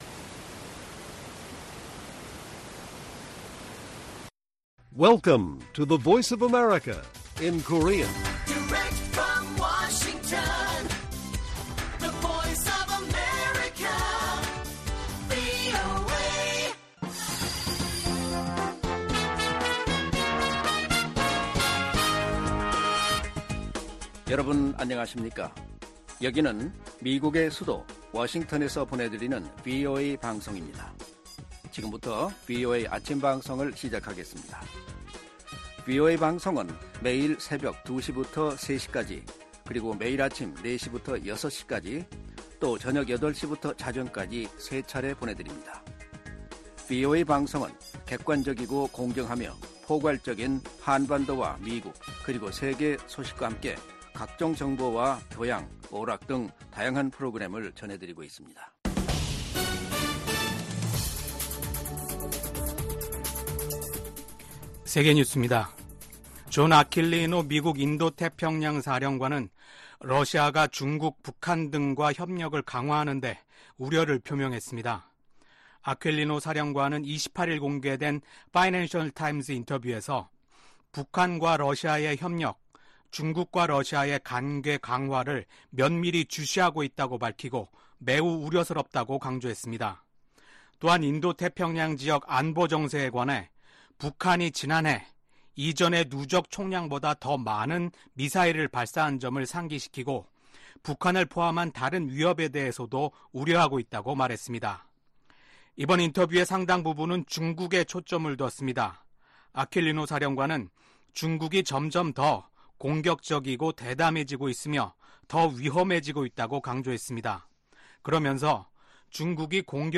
세계 뉴스와 함께 미국의 모든 것을 소개하는 '생방송 여기는 워싱턴입니다', 2024년 4월 30일 아침 방송입니다. '지구촌 오늘'에서는 조 바이든 미국 대통령과 베냐민 네타냐후 이스라엘 총리가 28일 또다시 전화 통화를 하고 가자지구 휴전 방안과 인질 석방 문제 등을 논의한 소식 전해드리고, '아메리카 나우'에서는 가자전쟁을 반대하는 시위가 미 전역의 대학으로 확산하면서 시위 참가자 900여 명이 경찰에 연행된 이야기 살펴보겠습니다.